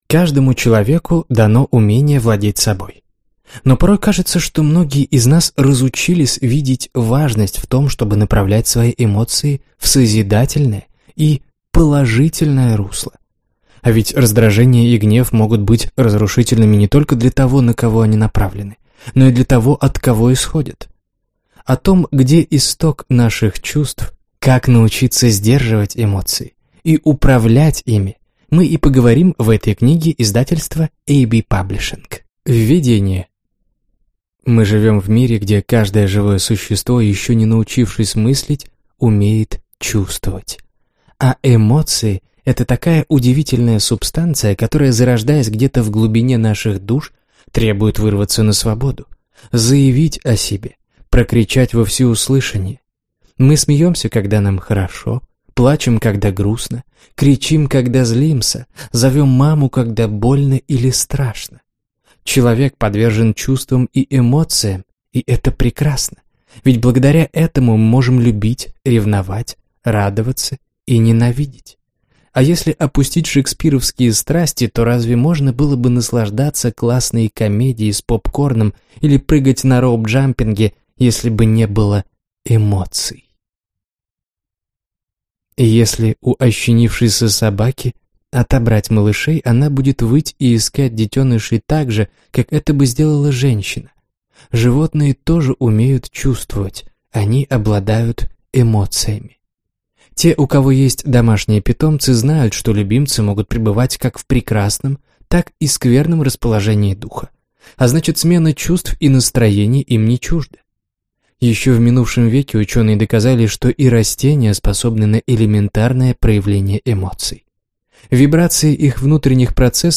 Аудиокнига Как контролировать себя и других | Библиотека аудиокниг